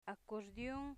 acordion accordion Part of Speech noun Acquisition Method Conversations Etymology Spanish Phonological Representation a'koɾdion accordion acordeón [Spanish] acordion [Quichua] Comment Es un instrumento musical de viento.